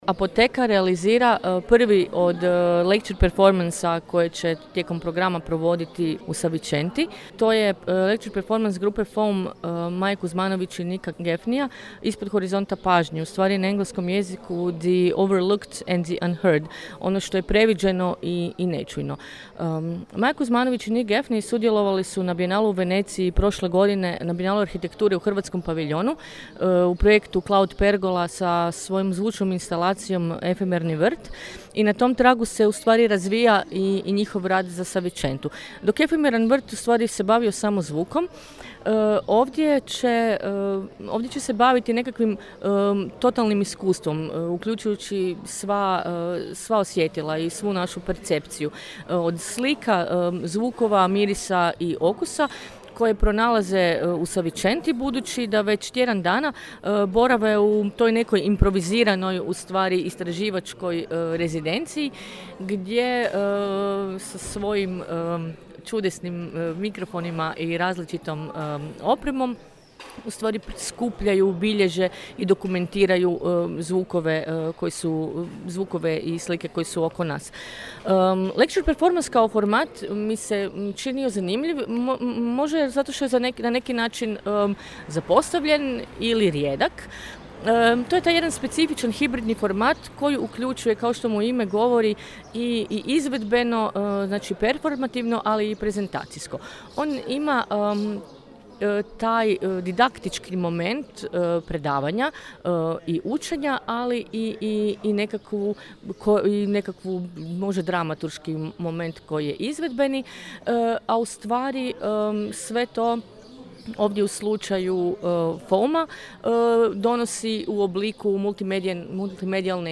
FoAM-ov ‘lecture performance’ u Gradskoj loži u Savičenti istražuje tihe, liminalne i često previđene odlike Istre.
Šuštanje borovih iglica na povjetarcu Maestrala.